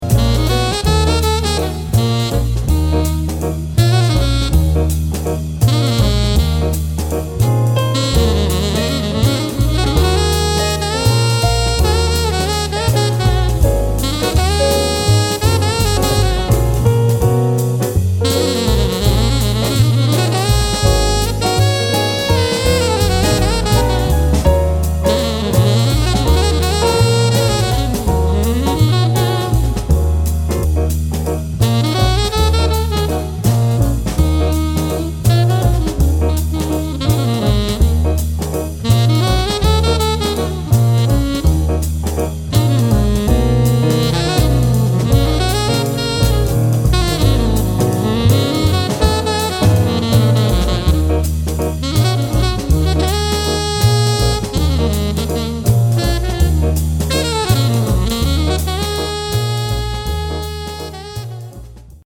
Jazz standards
From £648 + travel | Saxophonist